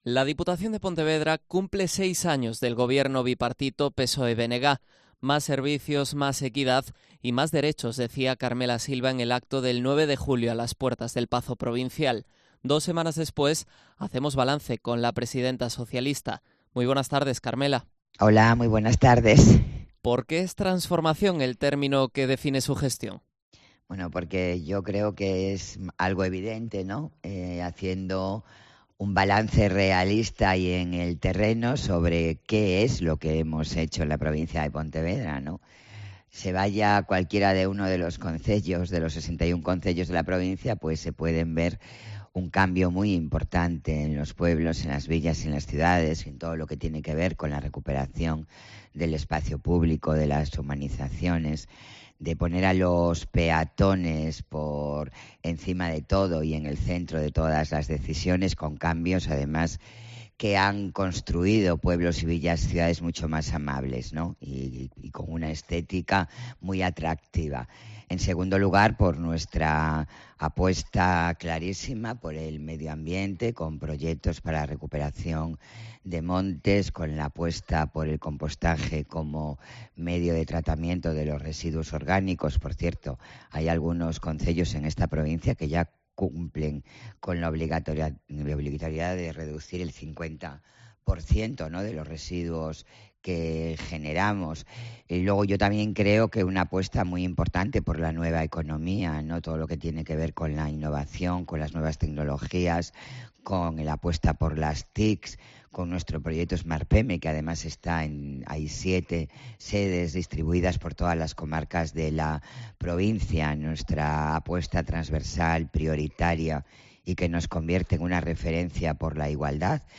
Entrevista a Carmela Silva, presidenta de la Deputación de Pontevedra